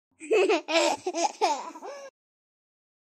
哇哇哇.MP3